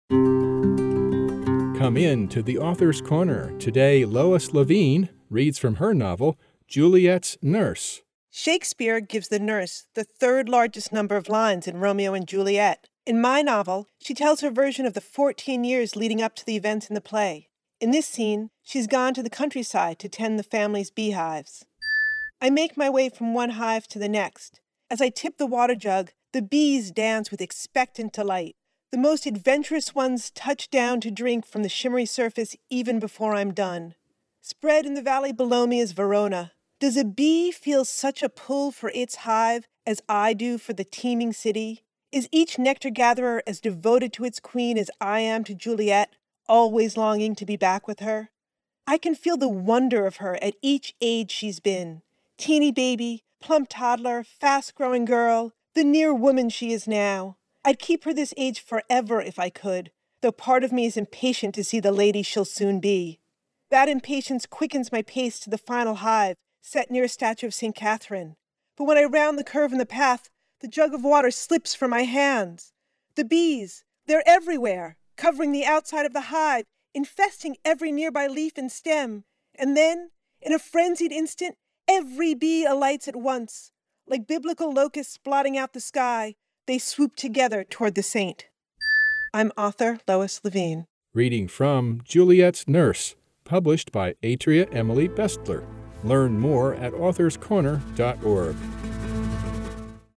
We were recording an 80-second reading from my novel.